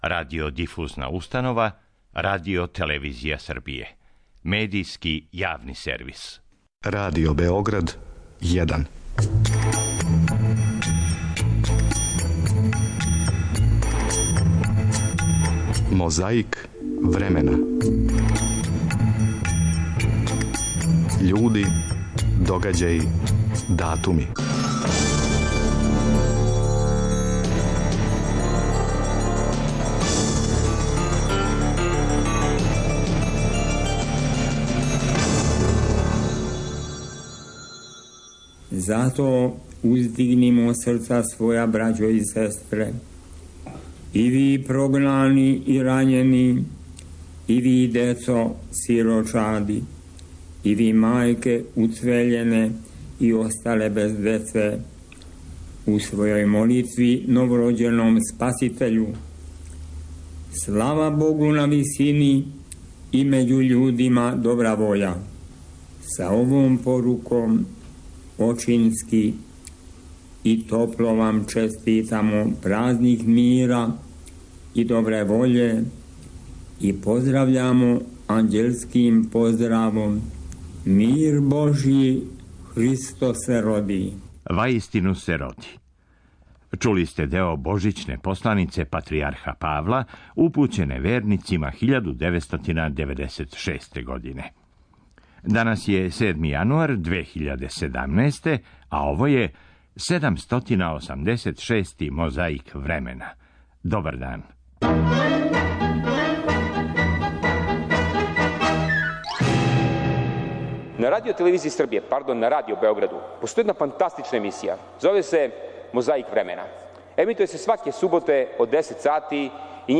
Слушаоцима Радио Београда, у емисији "Ризница", прочитана је посланица Патријарха Германа.